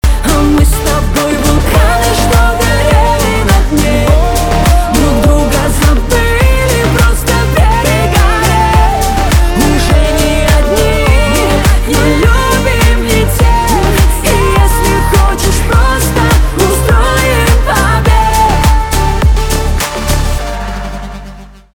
битовые , басы , дуэт